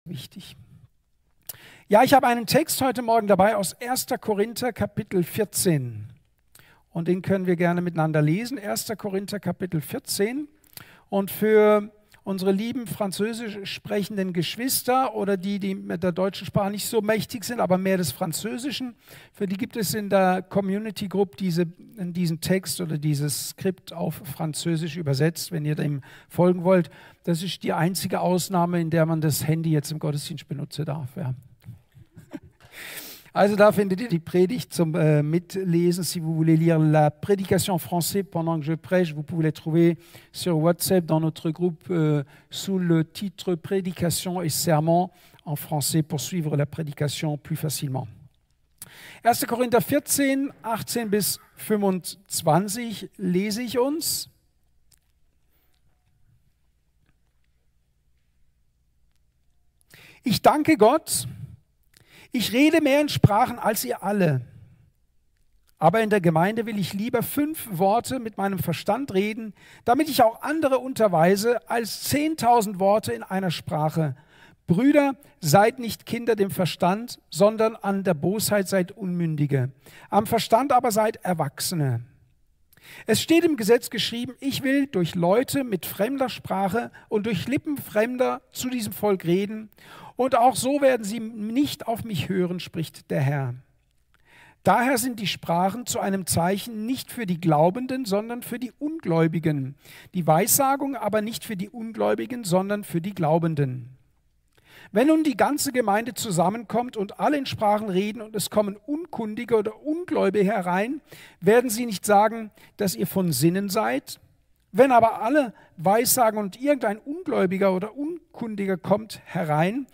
Thema: Redet in neuen Sprachen! Datum: 31.12.2023 Ort: Gospelhouse Kehl